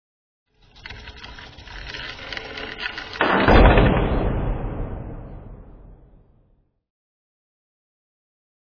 door1.mp3